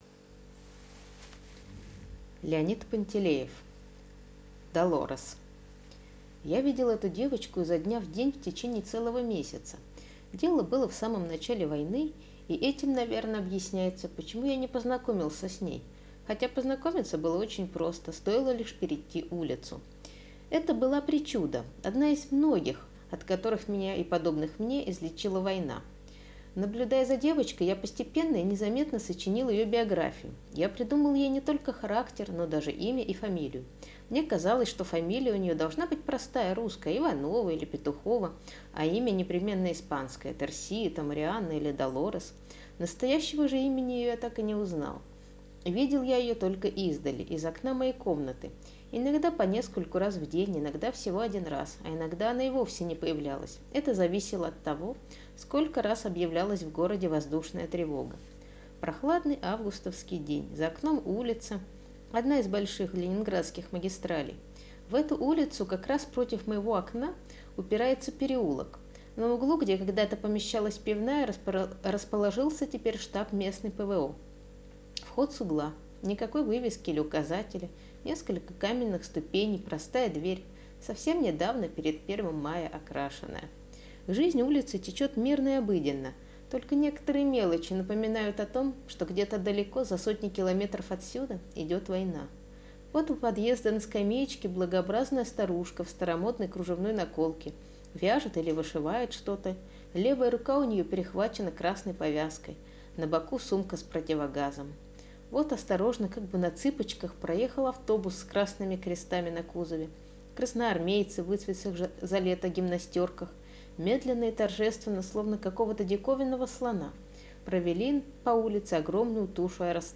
Аудиорассказ «Долорес»